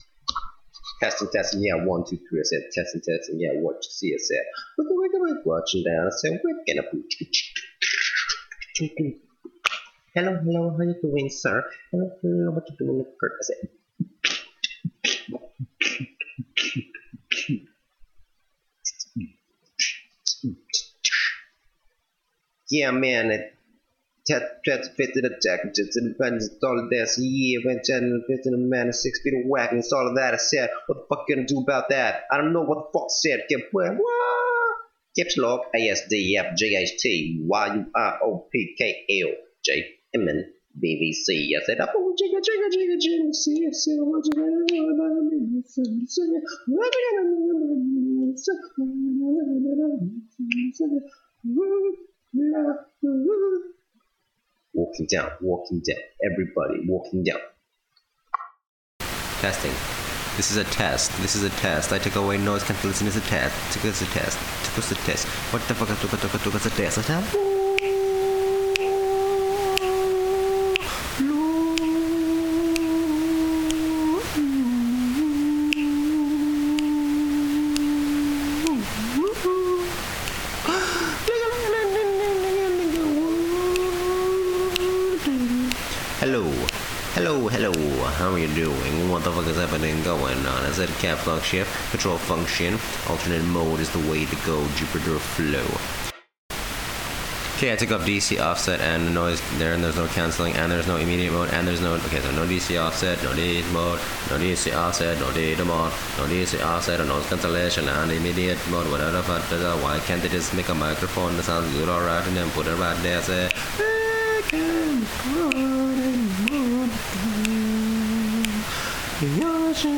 Track starts after 2 min or so.